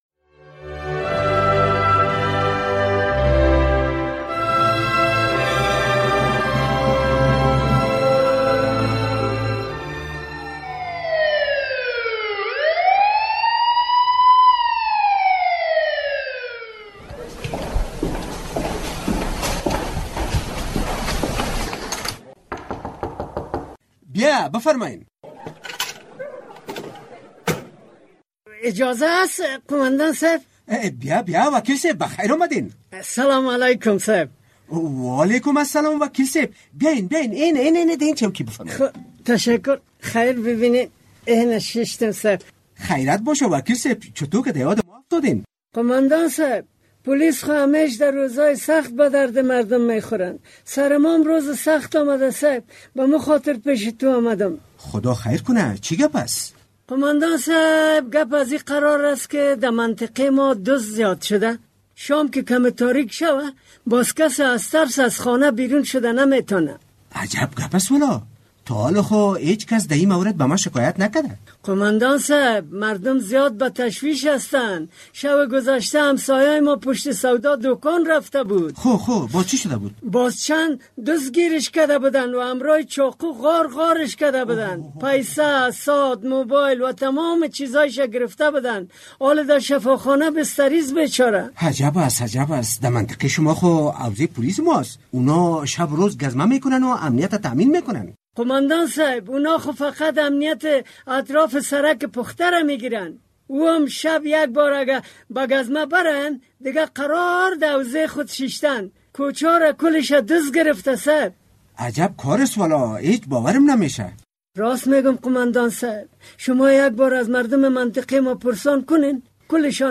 درامه کاروان زهر